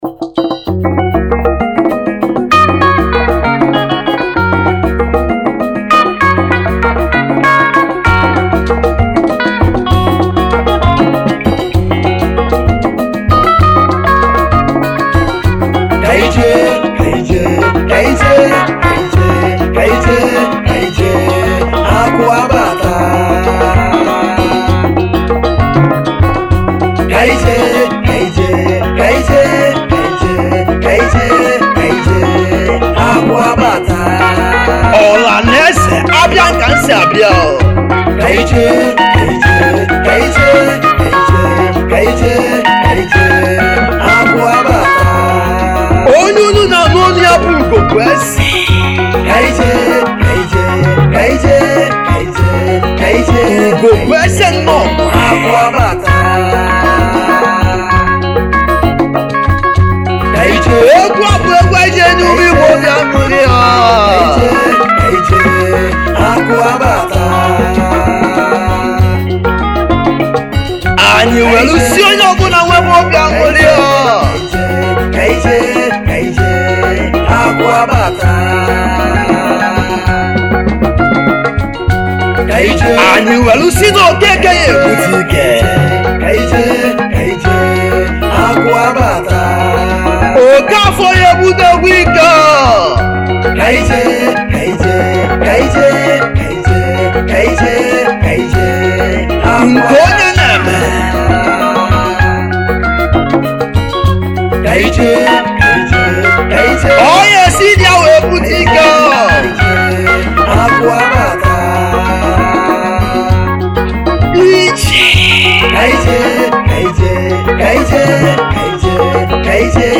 Category : Highlife
Highlife Traditional Free